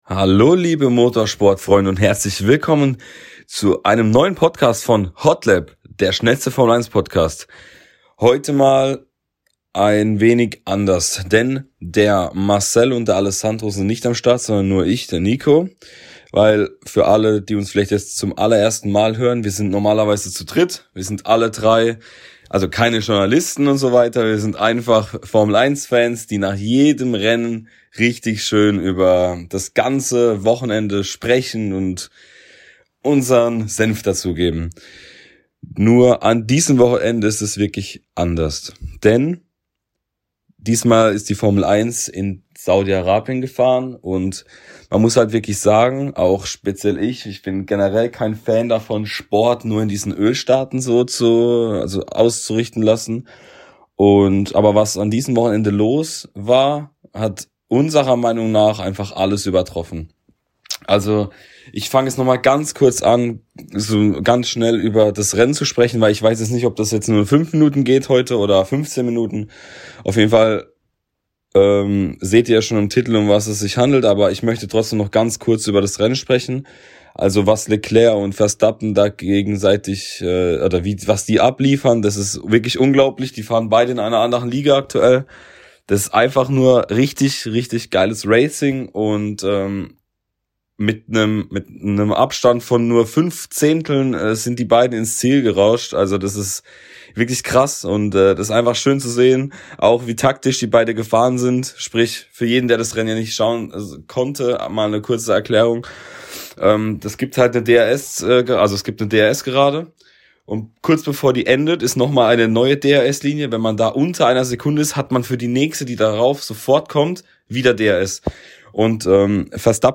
Wir sind drei F1 Fans, die nach jedem Rennen über alle Geschehnisse sprechen & diskutieren.